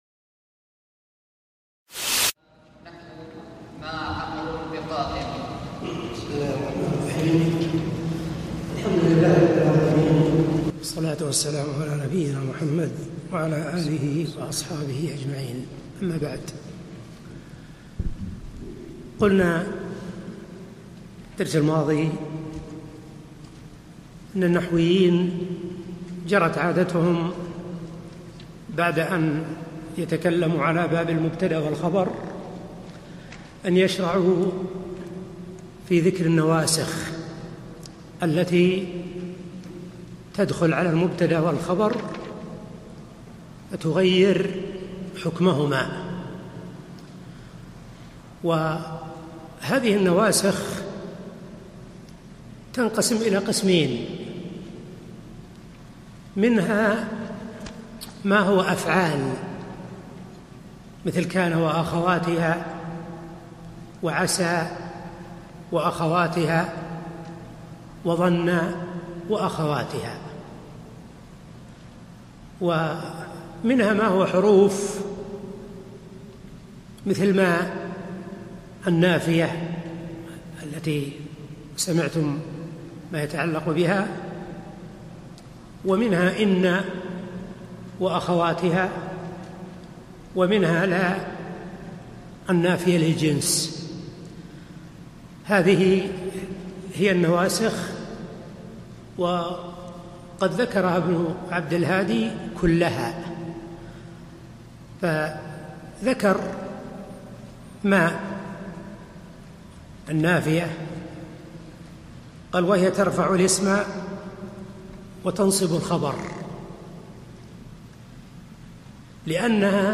الدورة العلمية 15 المقامة في جامع عبداللطيف آل الشيخ في المدينة النبوية لعام 1435
الدرس الثالث